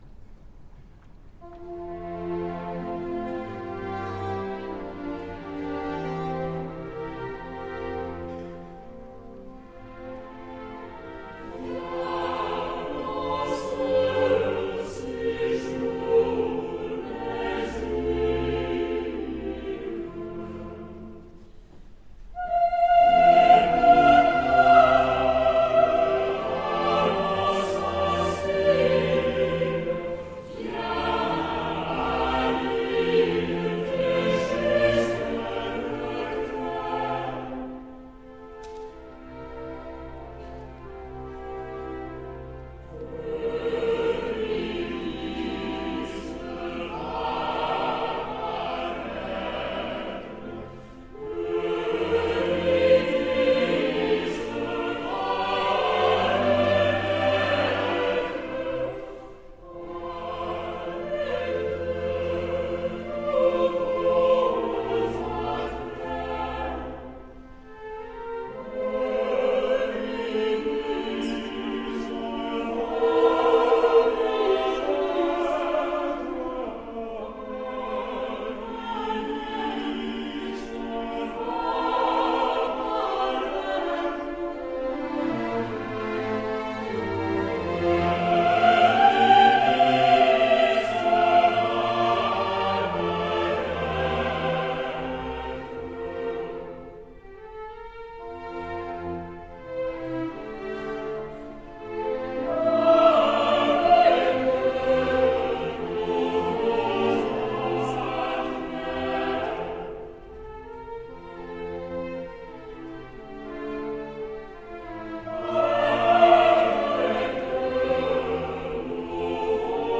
Orfeo_Euridice_10-91_Coro.mp3